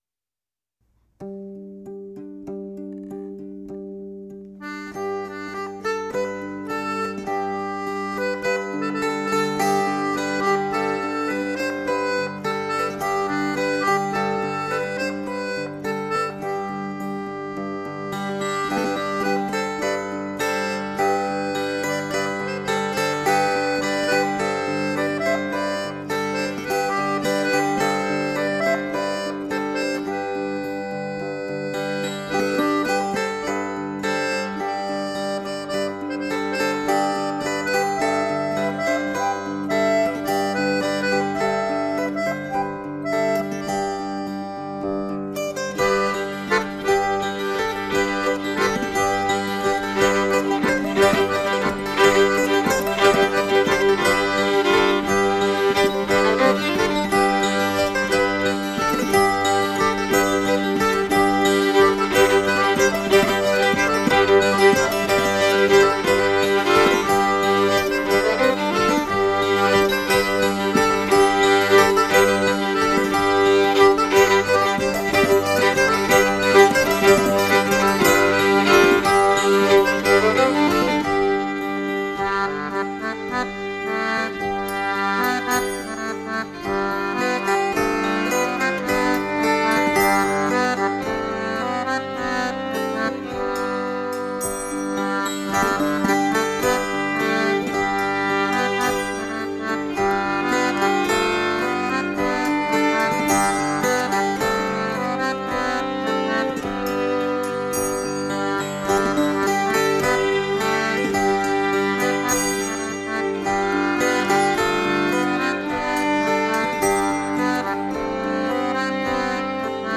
mélange de deux branles à 6 temps bien connus 4'27